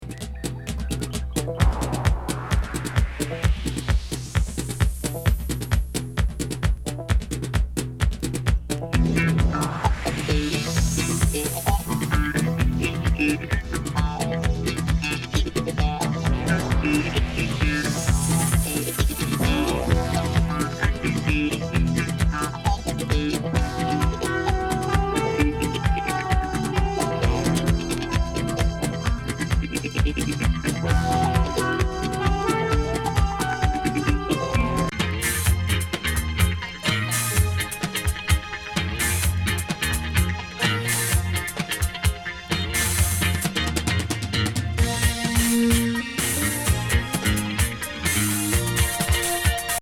ド演歌スプートニック・シンセ・ブギー